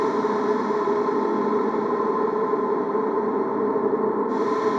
Index of /musicradar/sparse-soundscape-samples/Creep Vox Loops
SS_CreepVoxLoopB-05.wav